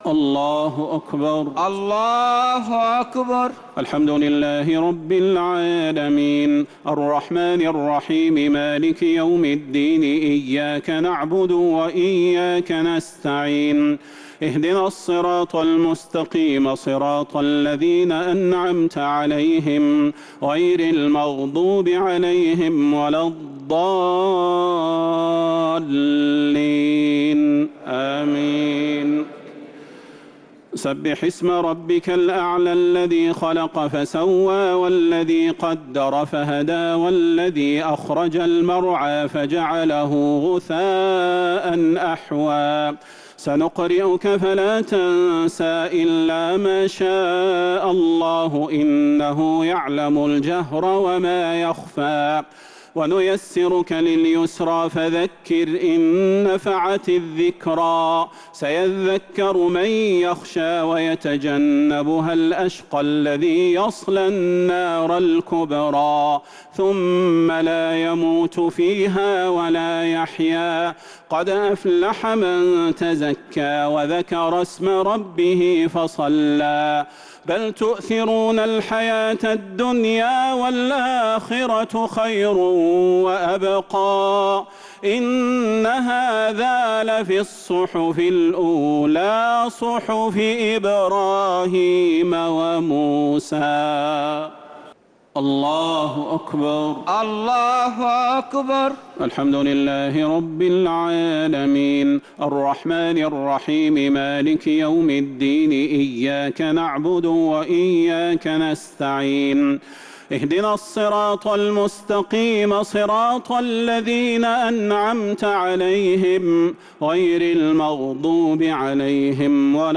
صلاة الشفع و الوتر ليلة 14 رمضان 1447هـ | Witr 14th night Ramadan 1447H > تراويح الحرم النبوي عام 1447 🕌 > التراويح - تلاوات الحرمين